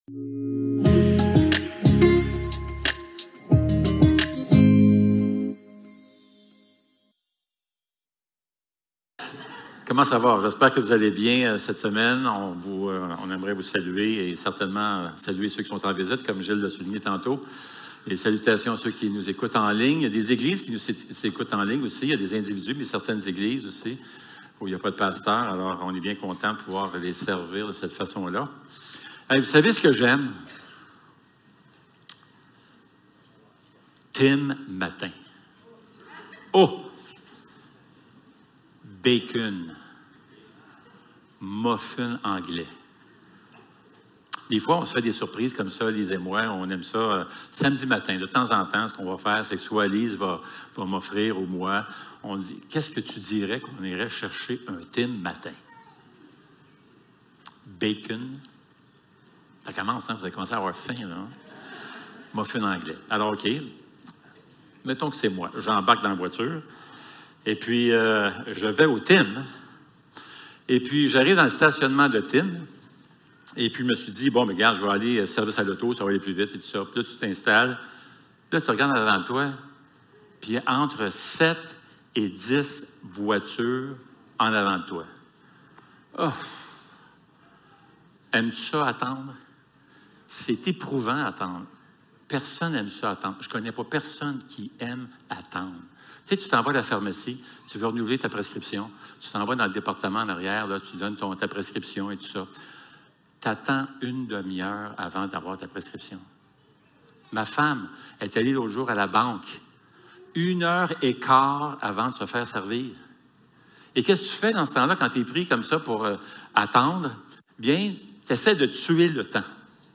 Actes 25 Service Type: Célébration dimanche matin Envoyés #37 Attendre n’est jamais amusant.